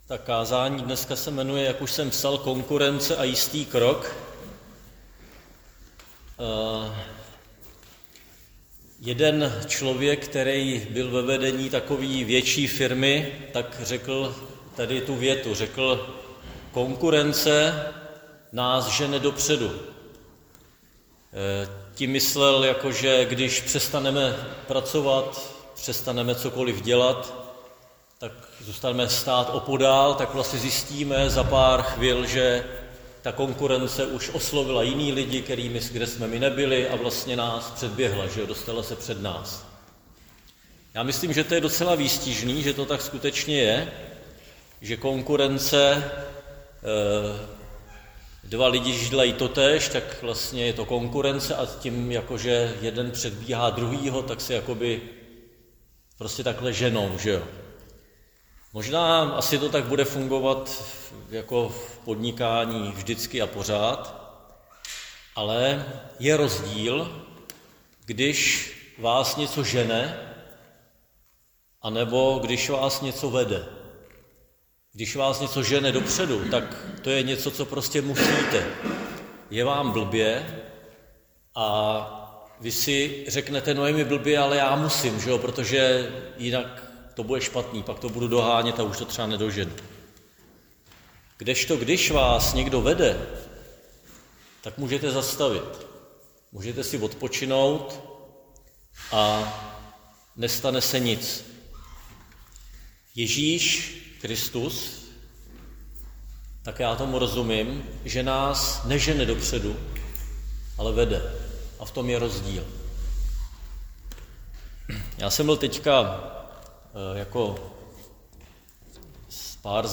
Křesťanské společenství Jičín - Kázání 17.3.2024